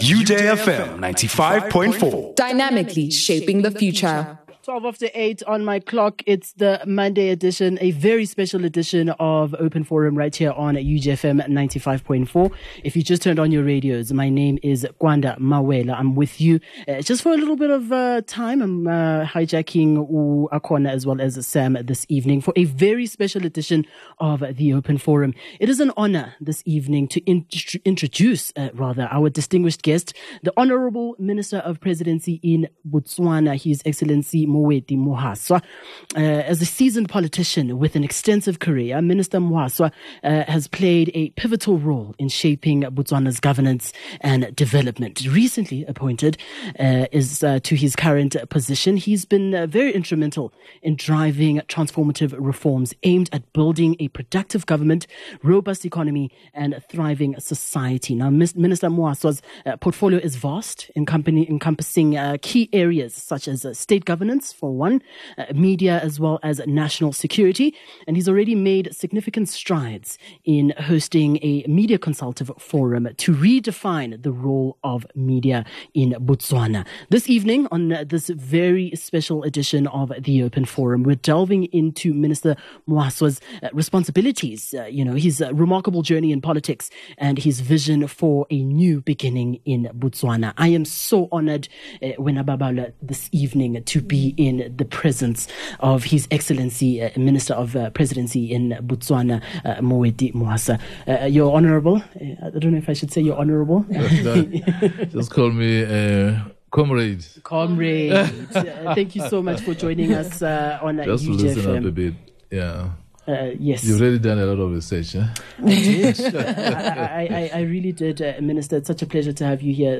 EXCLUSIVE INTERVIEW! We sat down with the Honourable Minister of State Presidential in Botswana, Moeti Mohwasa, and gained unparalleled insight into his vision for the country's future! From his remarkable journey in politics to his current role as Minister, Mohwasa shared his thoughts on The future of Botswana's economy and how to drive growth, The importance of good governance and transparency and His priorities for the Ministry and how to achieve them.